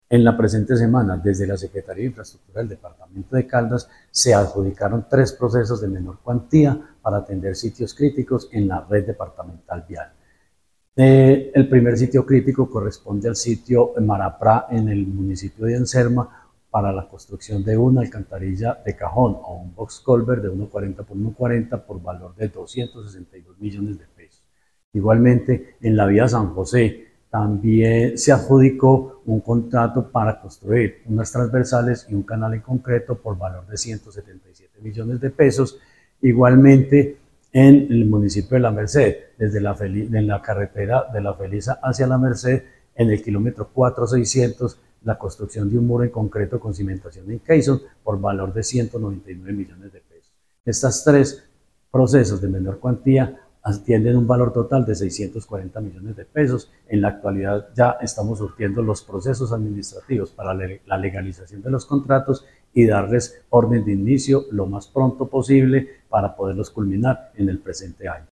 Jorge Ricardo Gutiérrez, secretario de Infraestructura de Caldas.